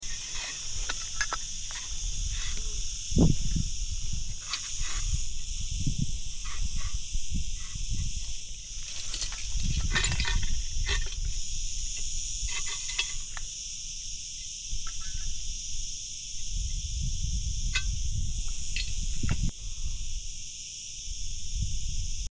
metal detector